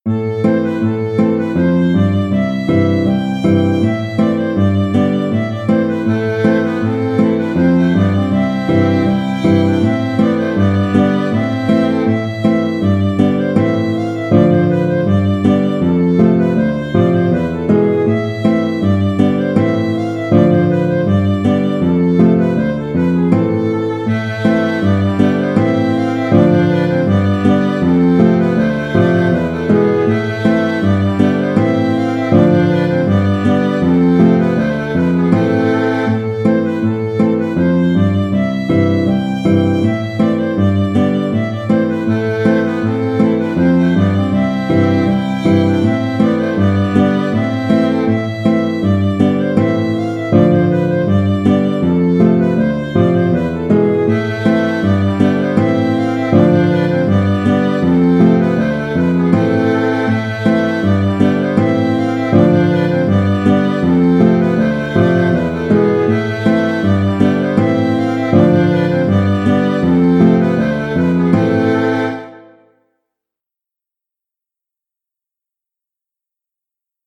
Musique bretonne
Kas a barh